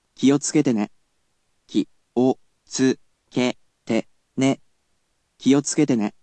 We’ll need to enlist the help of our lovely computer friend, QUIZBO™先生せんせい！
Click on the sound players to ask him to read something for you.